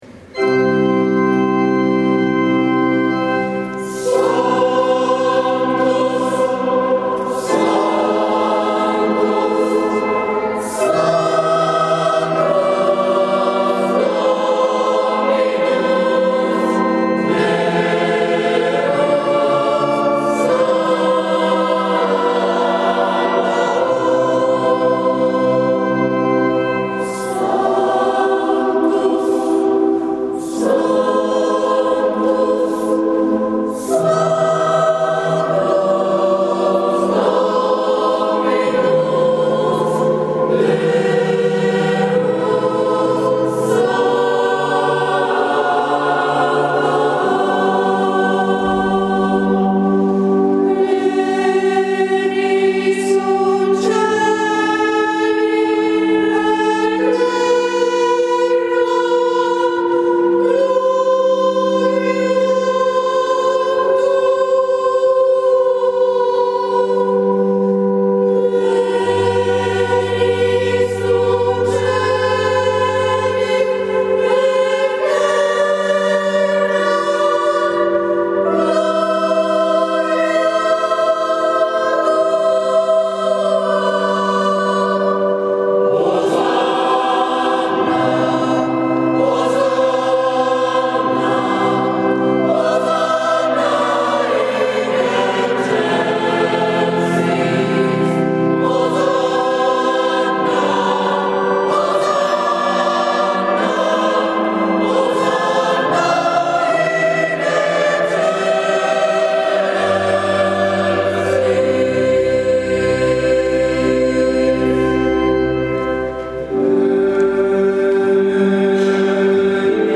Domenica 22 gennaio 2012 la corale ha animato la S. Messa nel Duomo di Bergamo Alta.